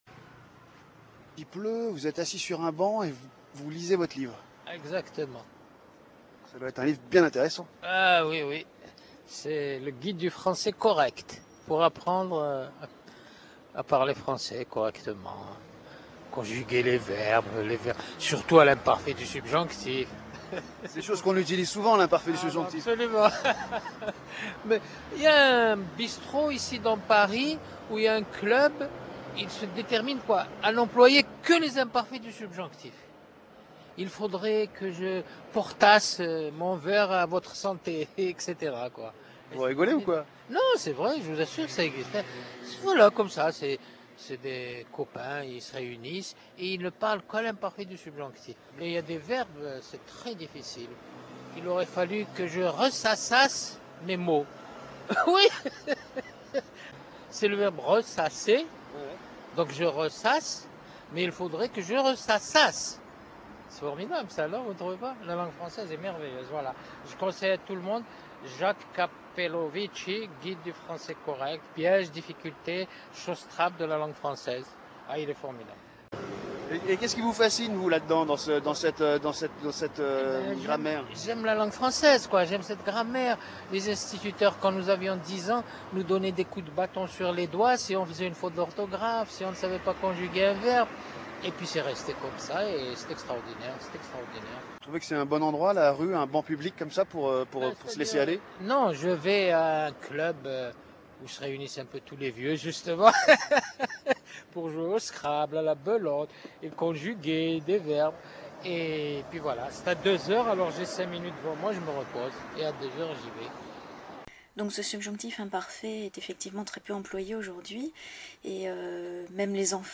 6. le scrabble: il prononce le nom de ce jeu comme la grande majorité des Français, à la française.